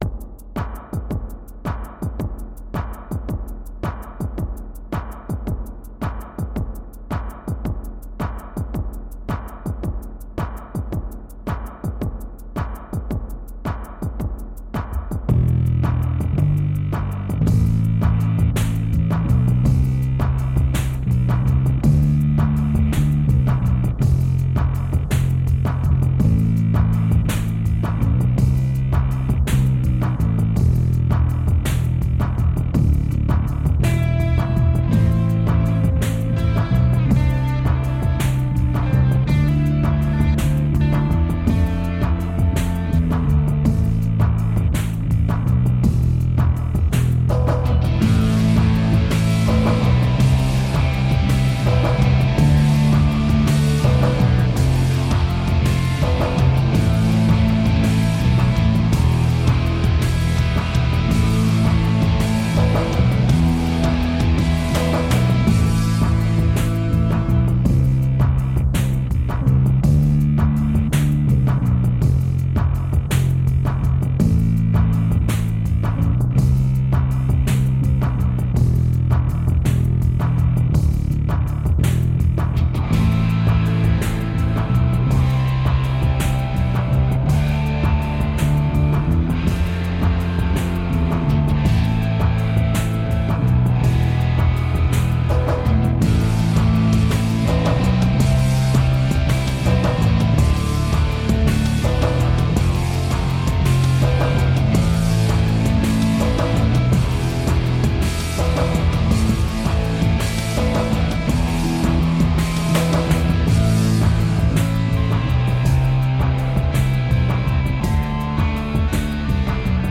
Tagged as: Electronica, Rock, Instrumental